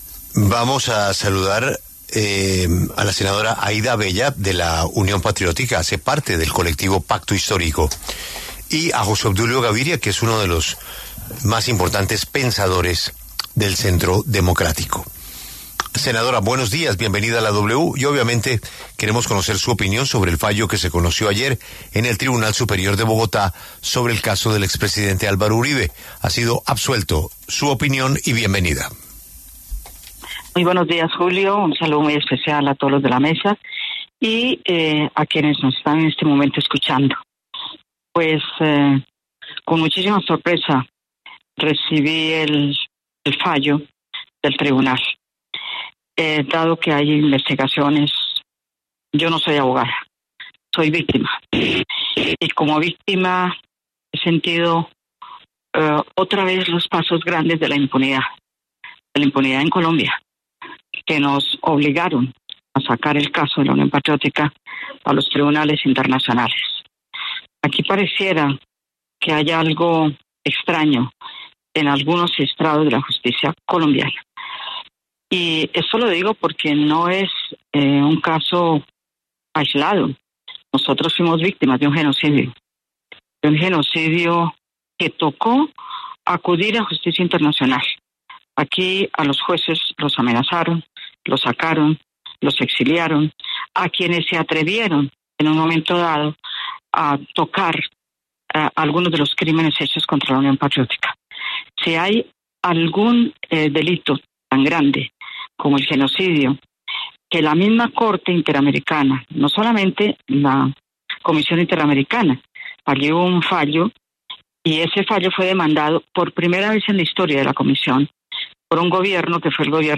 Debate: ¿Petro solo respeta las decisiones jurídicas que le gustan a él?